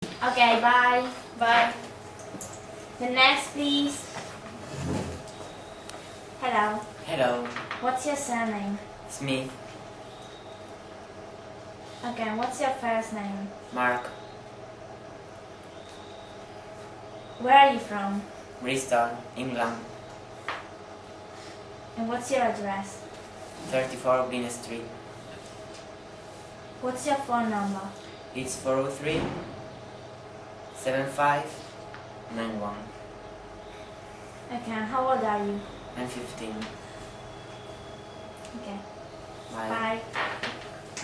Everyday conversations
En el video aparece una chica sentada enfrente con la que mantiene una conversación, el resto espera en cola en la puerta.